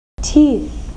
1 channel
teeth.mp3